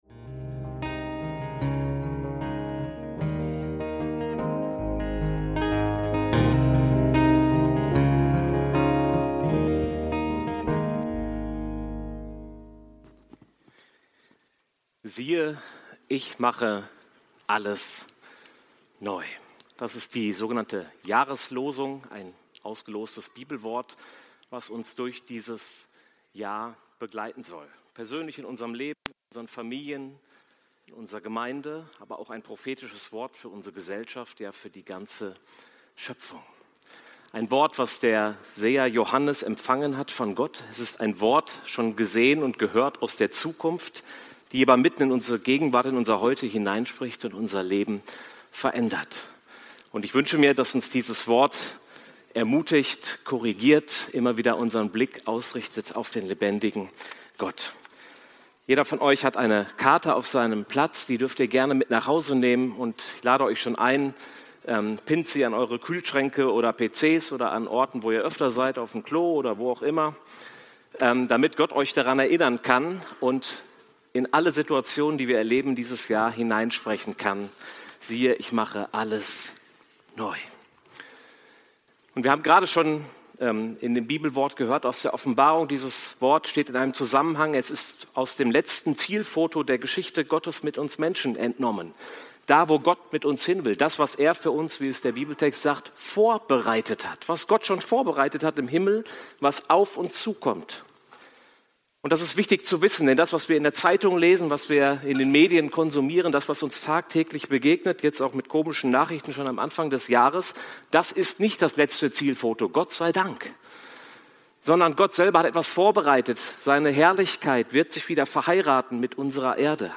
Siehe, ich mache alles neu – Predigt vom 11.01.2026